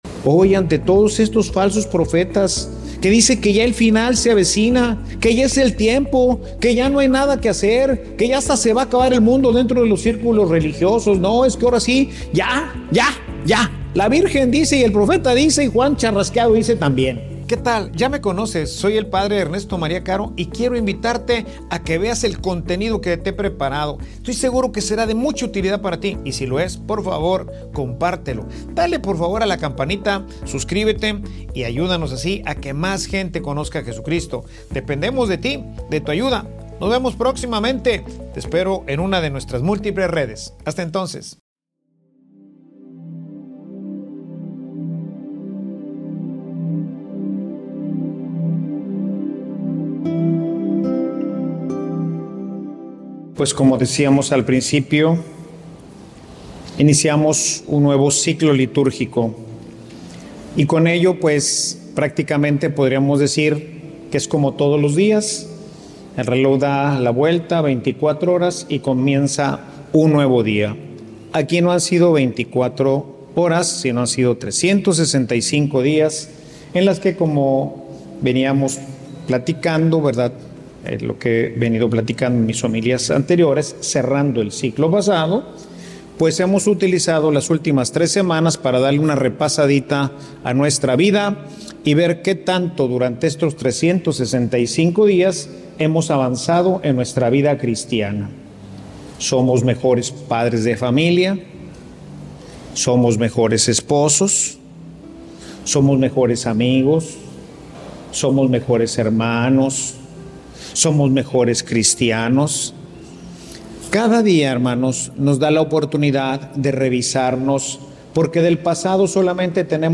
Homilia_La_aventura_de_ir_hacia_adelante.mp3